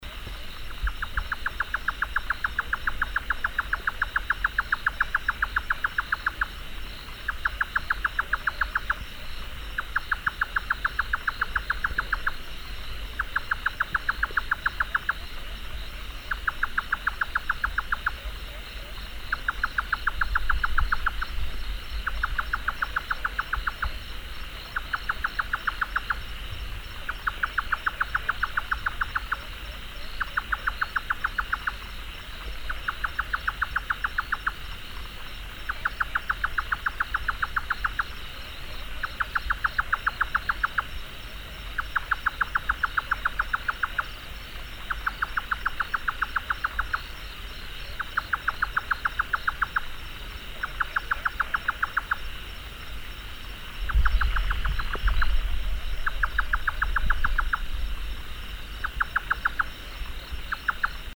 Engoulevent jotaka ( Caprimulgus jotaka )
Chant enregistré le 09 mai 2012, en Chine, province du Fujian, réserve de Dai Yun Shan.